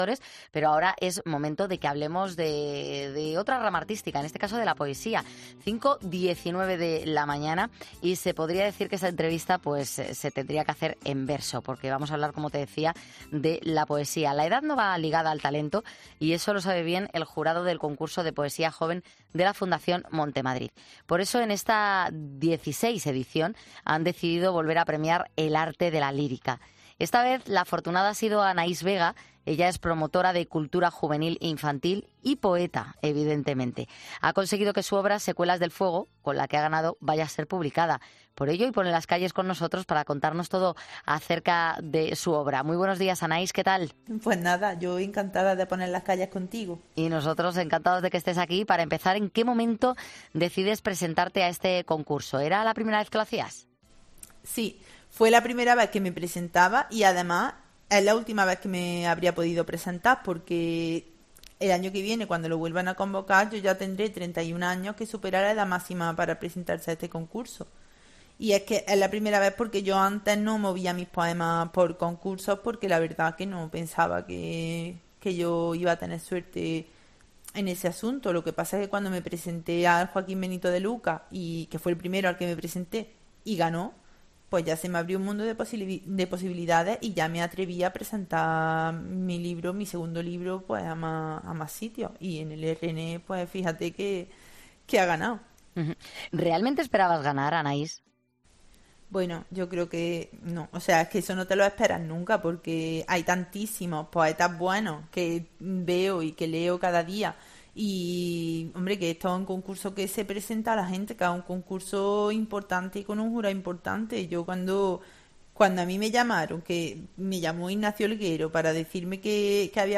Se podría decir que esta entrevista se va a hacer en verso porque de lo que vamos a hablar es de la poesía.